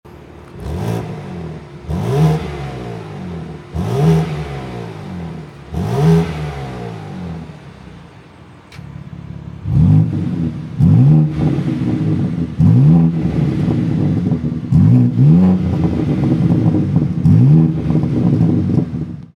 Listen to its 6-Cylinder roar...
Voodoo-blue-m3-sound-clip-revs.mp3